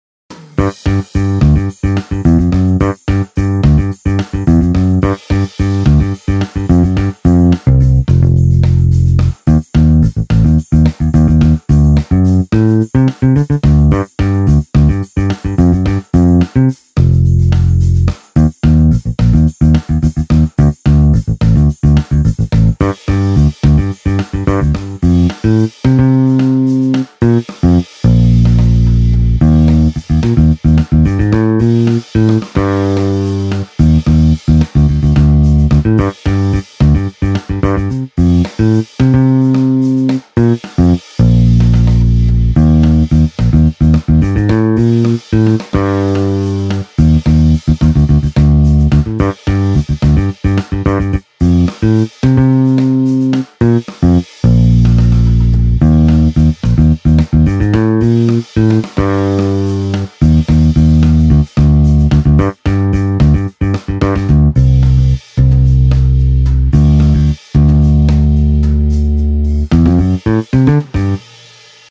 Dry Finger Bass